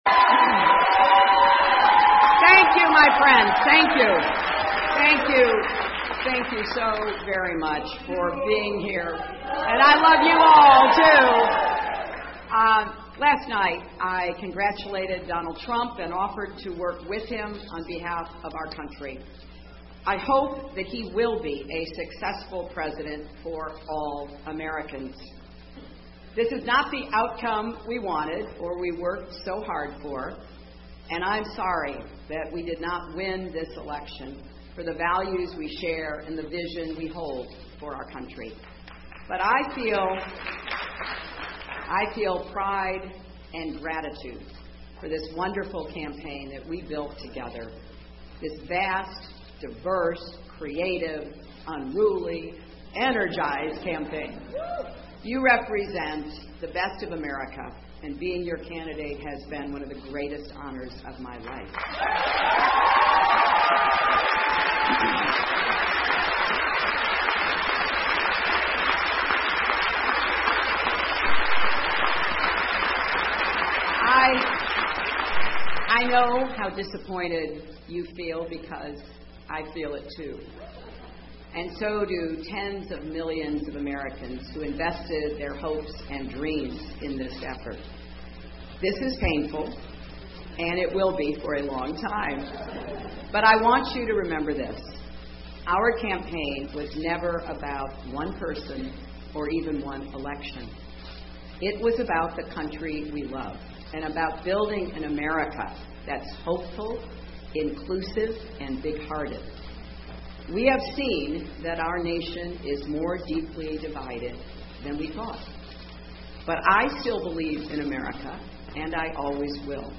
美国总统大选演讲 2016年美国总统大选希拉里败选演讲(1) 听力文件下载—在线英语听力室